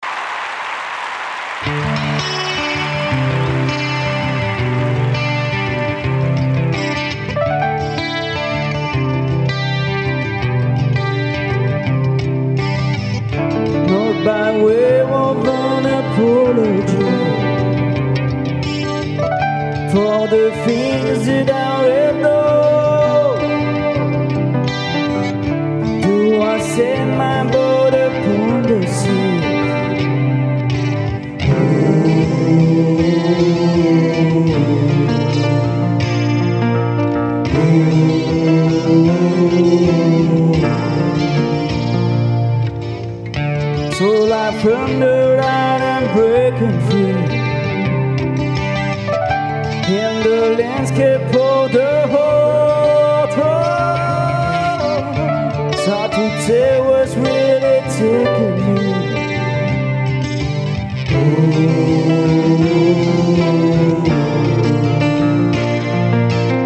Acoustic Set